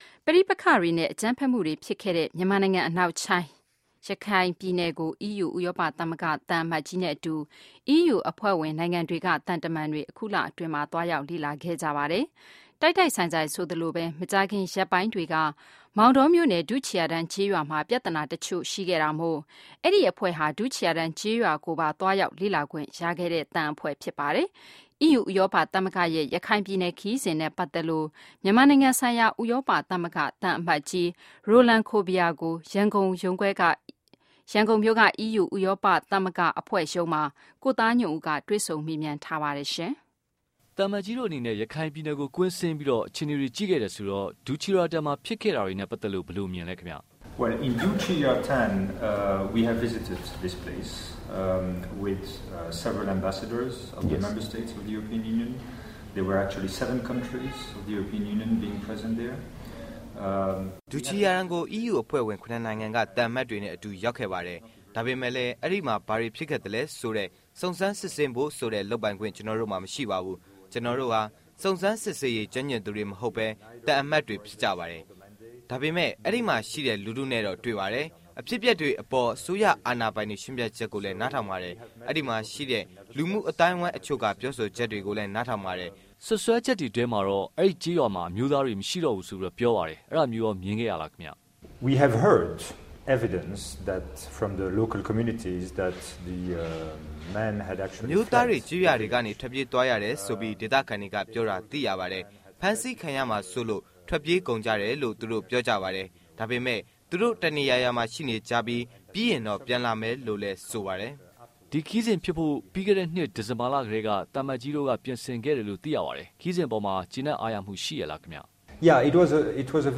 မြန်မာနိုင်ငံဆိုင်ရာ ဥရောပသမဂ္ဂသံအမတ်ကြီးနဲ့ တွေ့ဆုံမေးမြန်းခန်း